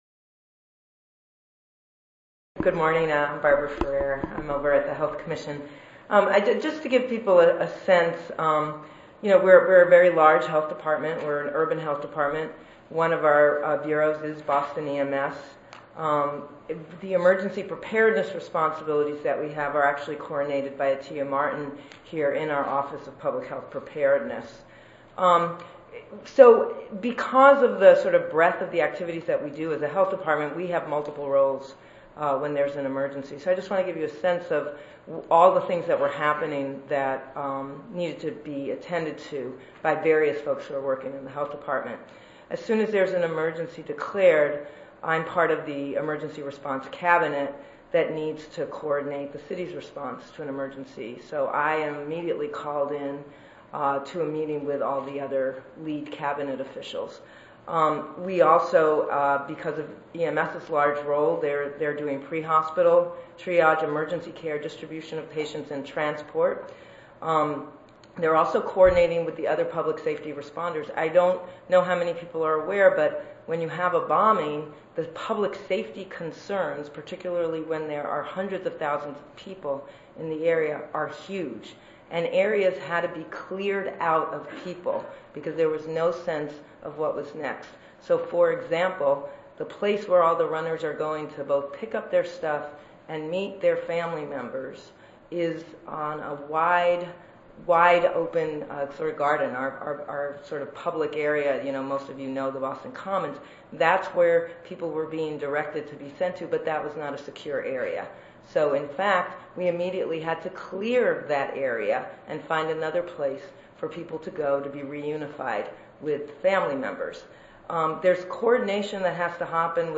141st APHA Annual Meeting and Exposition
Oral